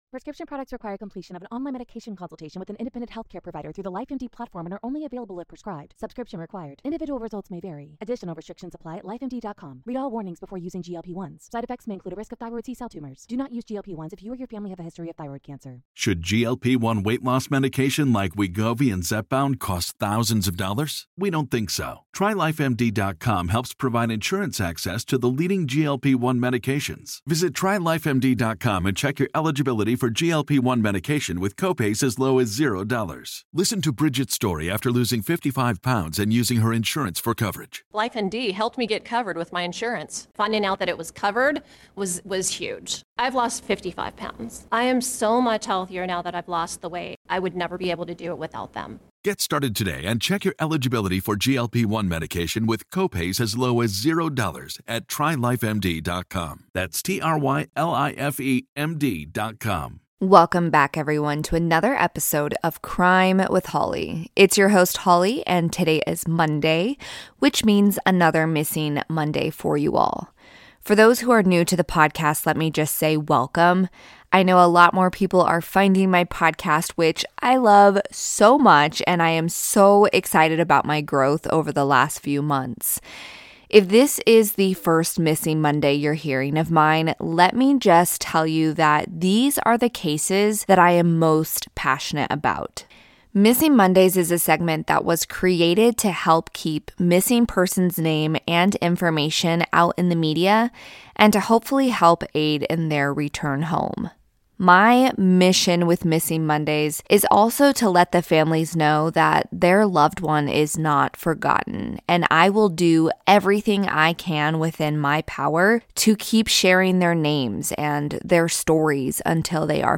*This episode was recorded previously for the purpose to be used on my old podcast. New introduction and ending have since been recorded and added, audio levels may reflect the different recordings.